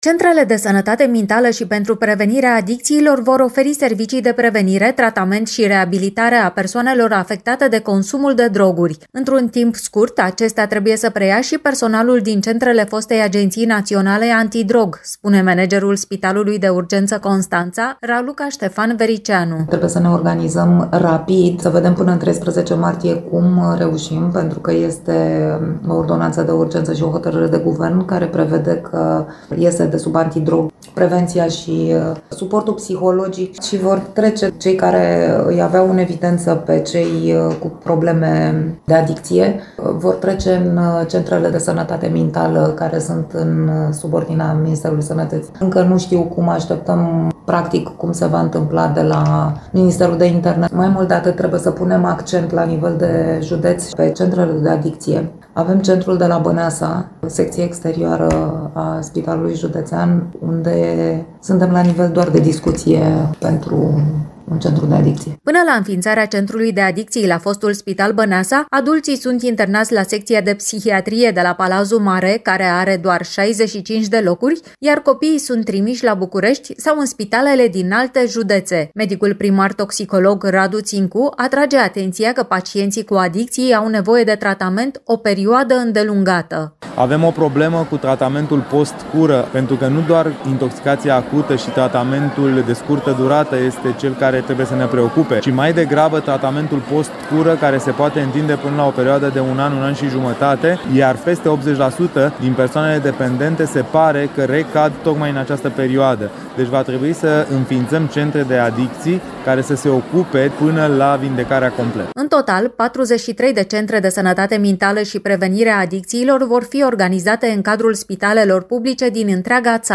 Detalii, în reportajul